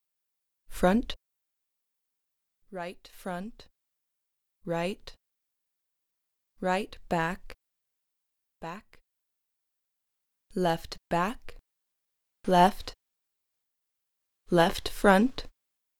horizontal_test_FuMa.wav